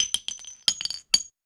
weapon_ammo_drop_12.wav